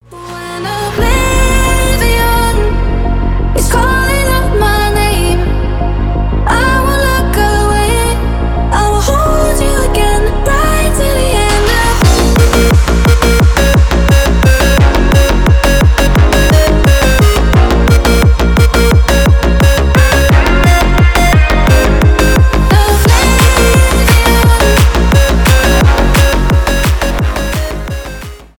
красивый женский голос
техно
клубные , нарастающие